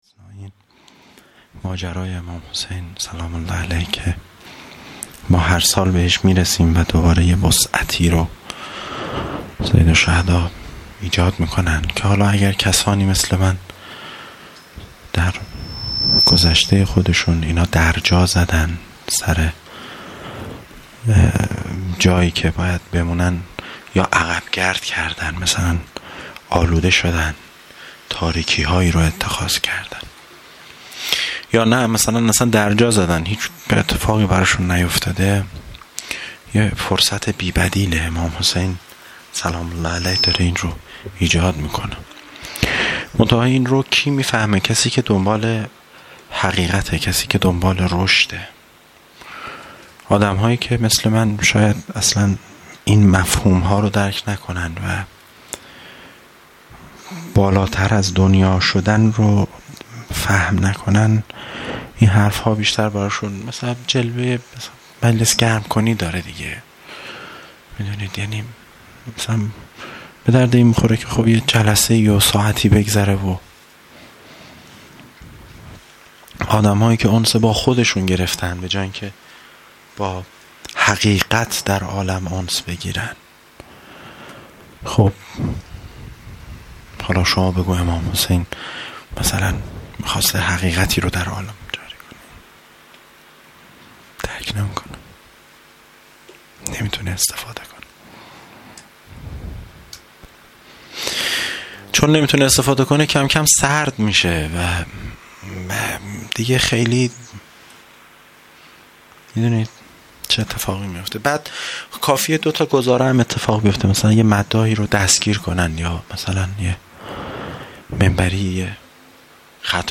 دوشنبه ۲۶ تیرماه ۱۴۰۲ - هیئت جوانان ریحانه الحیدر سلام الله علیها